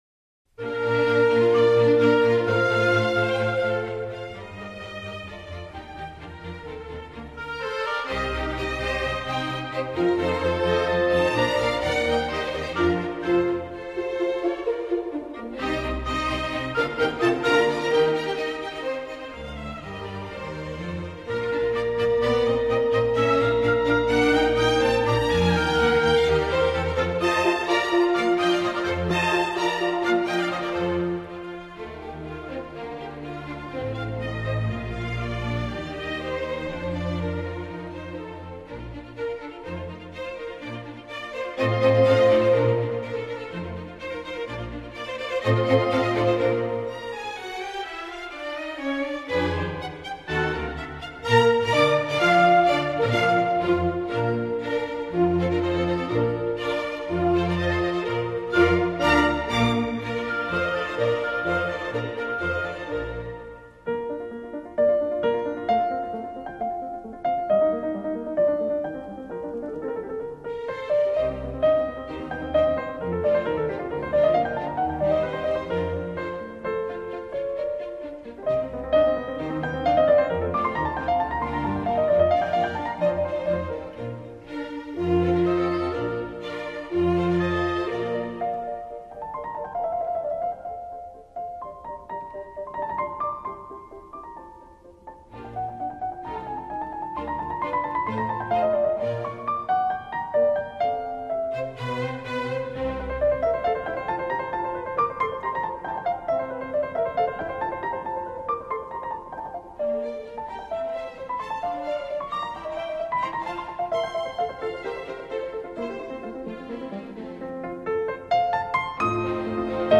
协奏曲